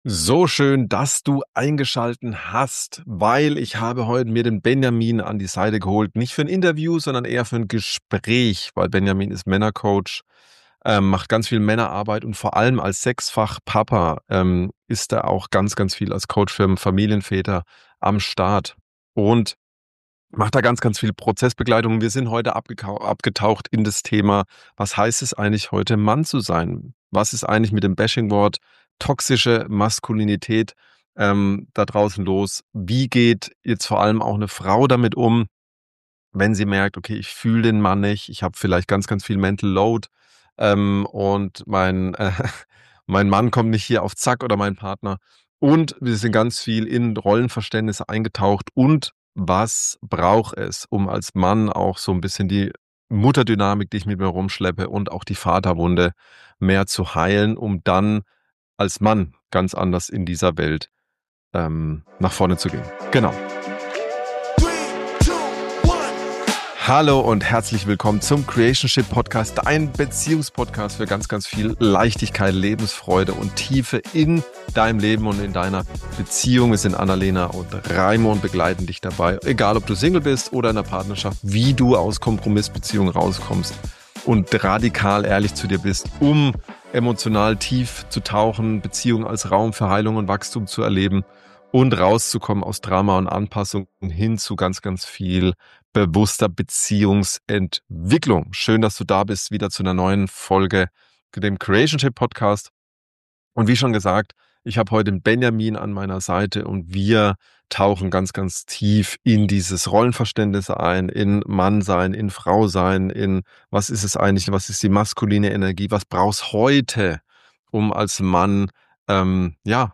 Beschreibung vor 3 Monaten Was bedeutet es heute wirklich, Mann zu sein – jenseits von toxischer Männlichkeit und emotionaler Anpassung? In diesem tiefgehenden Gespräch